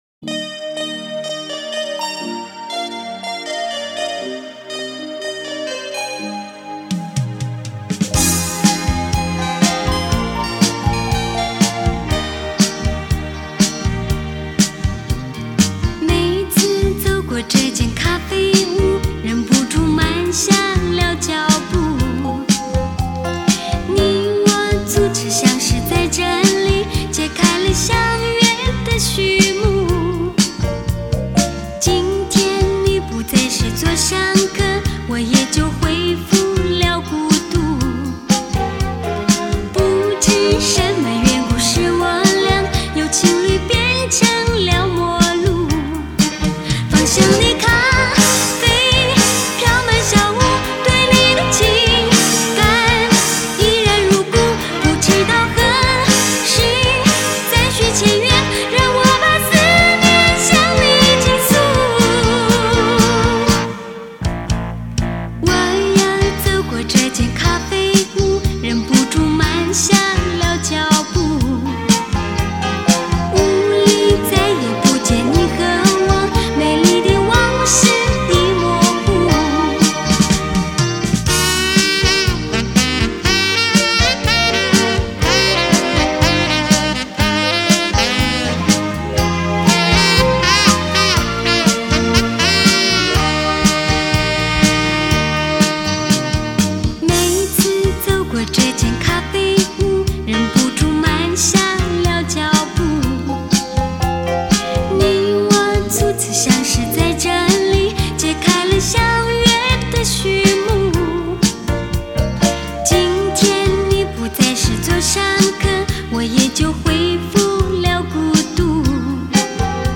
专辑类型：流行音乐